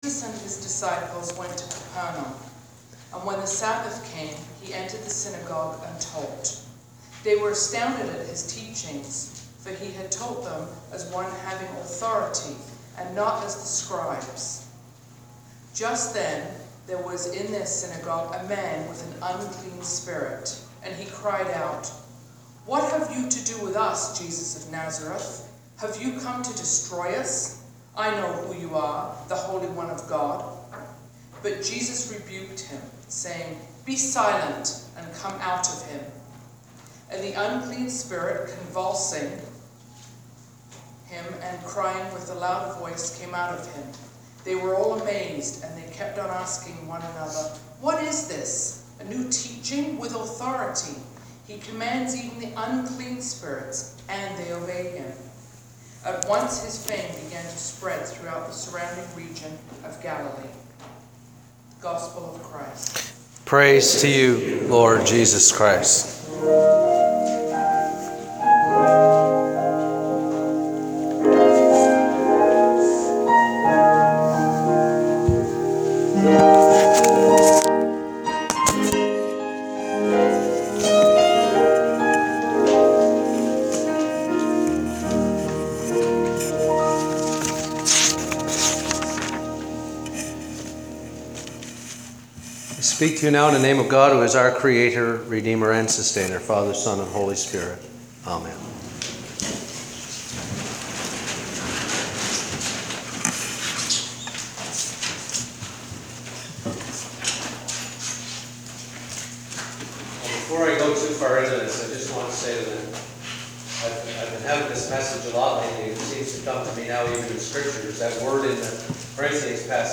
This past Sunday was the Fourth Sunday After Epiphany.